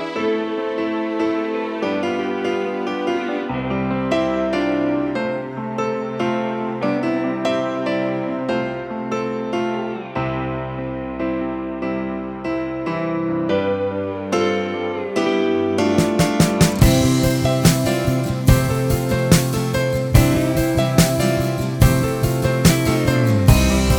no piano Pop (1970s) 6:21 Buy £1.50